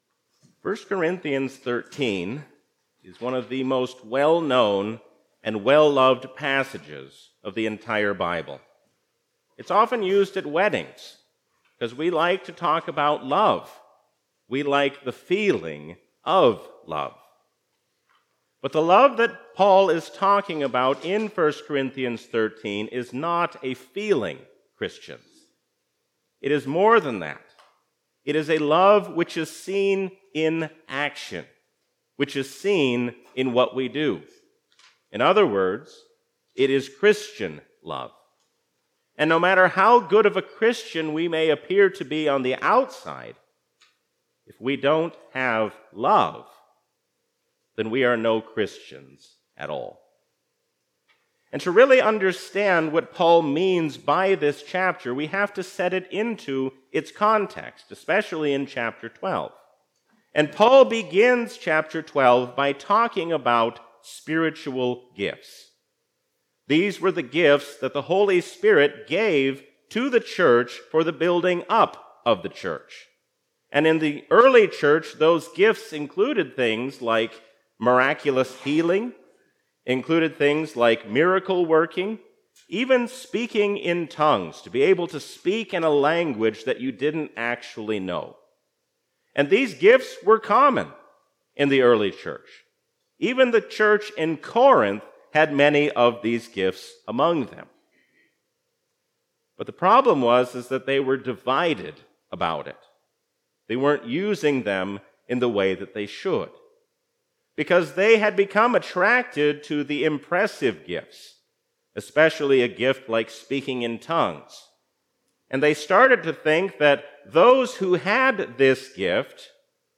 A sermon from the season "Trinity 2024." There can be no true Christian love without holding on firmly to Biblical truth.